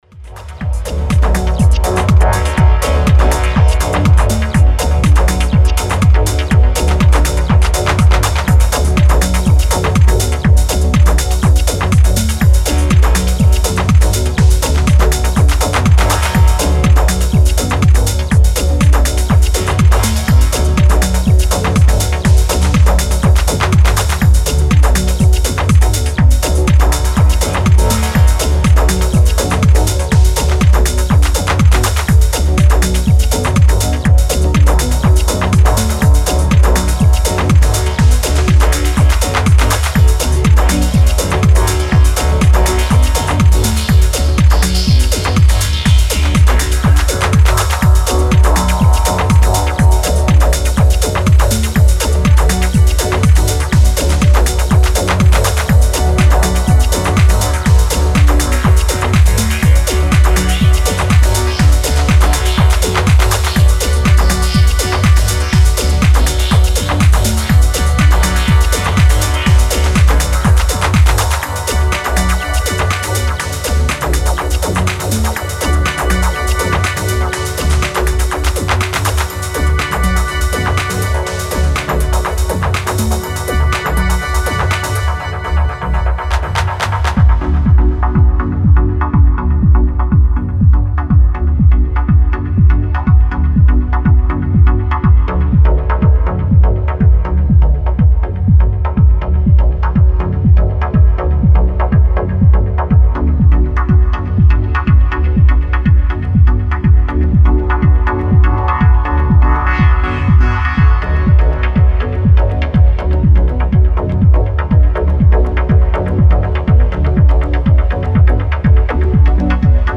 Style: Techno / Dub